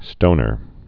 (stōnər)